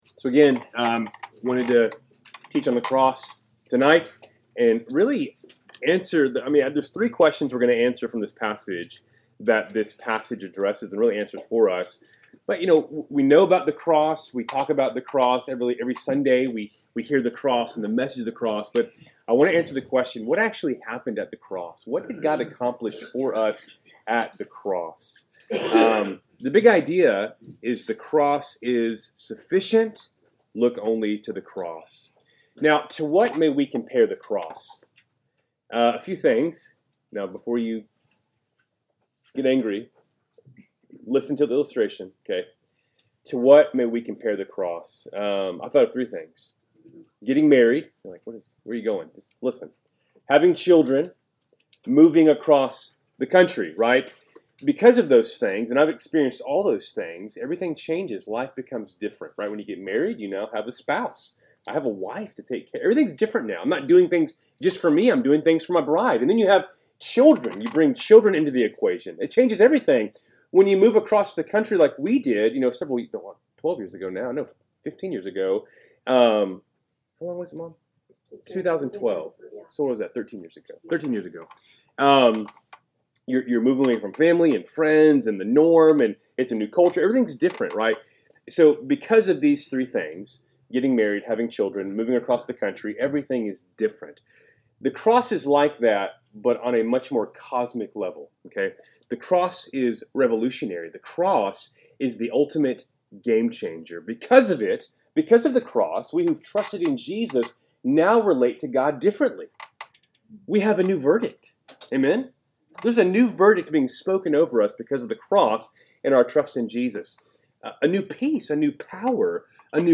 Wednesday Night Bible Study, April 16, 2025